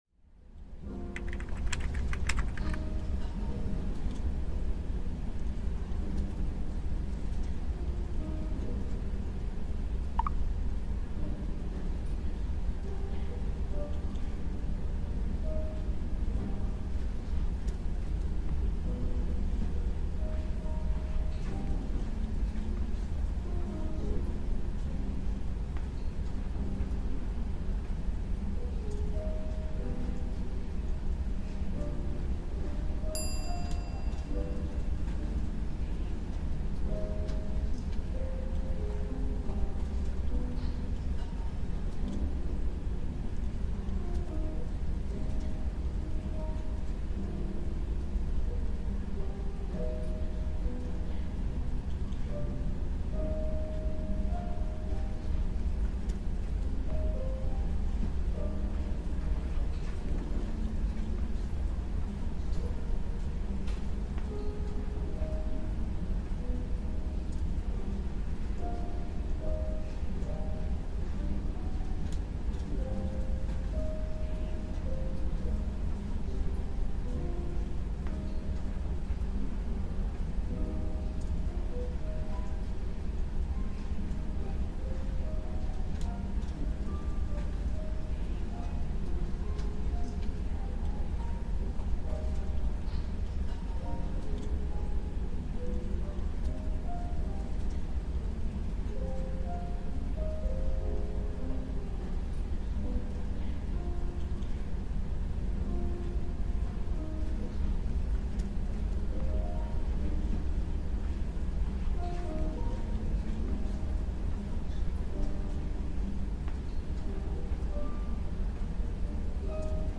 量子集中（v.6）– 集中力 / 集中 / 記憶力向上 | アイソクロニックトーン
勉強BGM